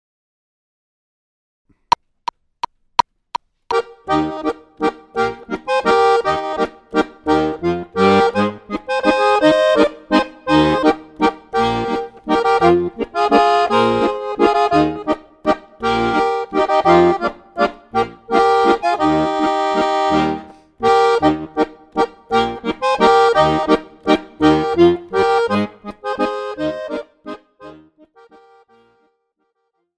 Besetzung: Tuba